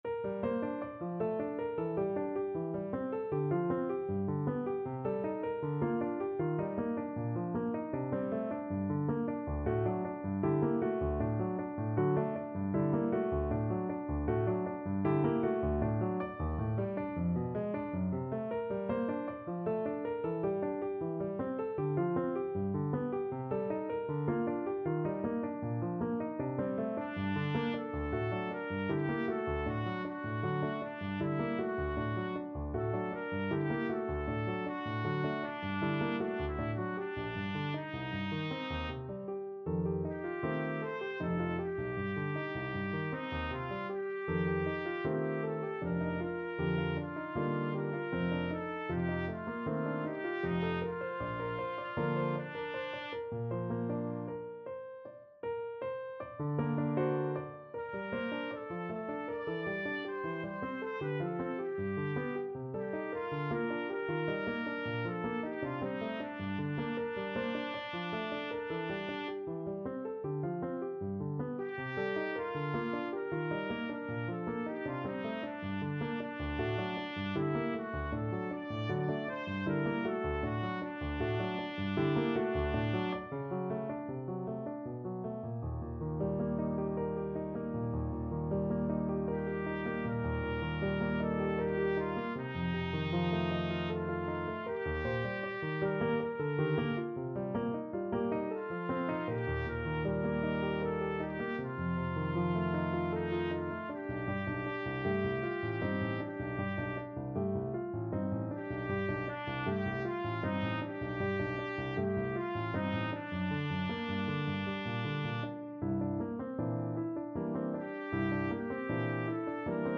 Trumpet version
3/4 (View more 3/4 Music)
Trumpet  (View more Intermediate Trumpet Music)
Classical (View more Classical Trumpet Music)